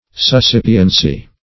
Suscipiency \Sus*cip"i*en*cy\, n.